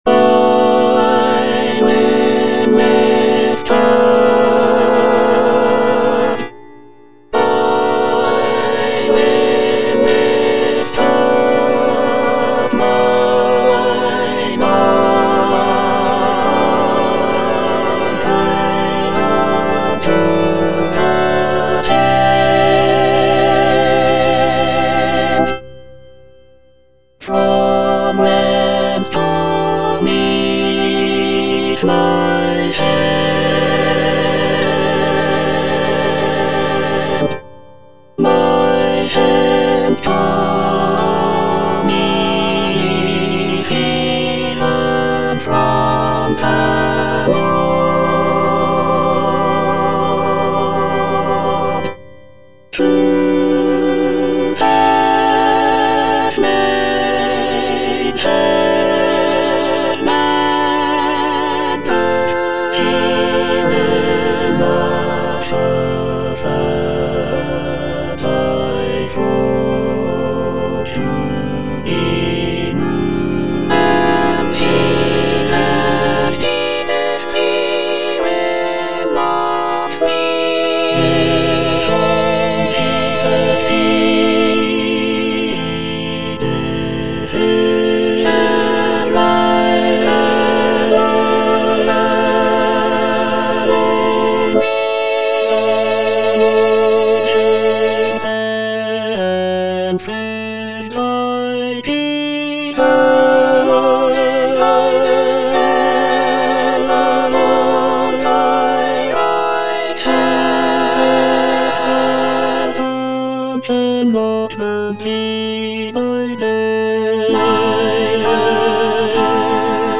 (All voices)
choral work